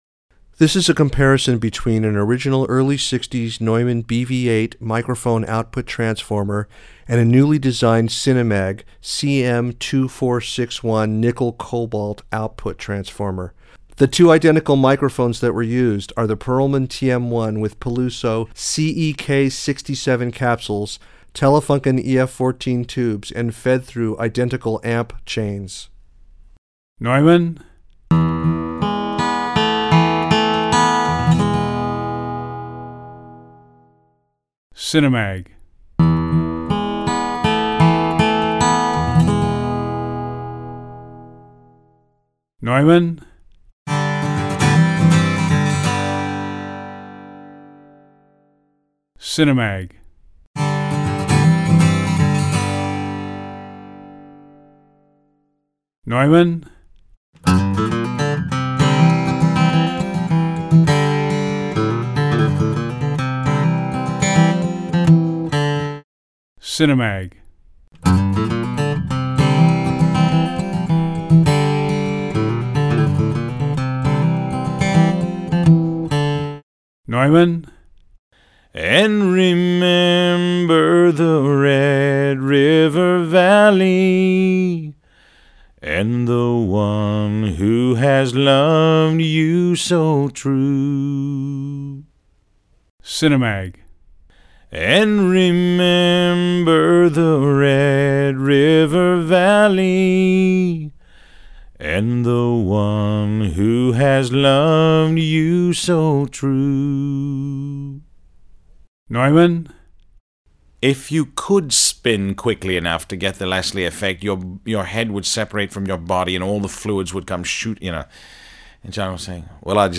SHOOTOUT AT ROTUND RASCAL RECORDING STUDIO
Present were a 1960s vintage U-47 and matched Pearlman TM-1 microphones.
For the session the mics were arranged with the capsules in very close proximity to each other.
Both microphones were fed through identical amplifier chains and great care was taken to eliminate all variables so that a good comparison could be made.
The objective was to give it a “cleaner” sound while maintaining “warmth.”
As can be seen from the compiled spectrogram of the session, the CM-2461NiCo has better low frequency response and a brighter high end.